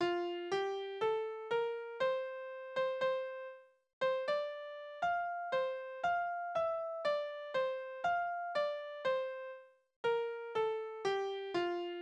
Kniereiterlieder: Wenn der Schneider reiten will
Tonart: F-Dur
Taktart: 4/4
Tonumfang: Oktave